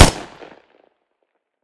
smg-shot-03.ogg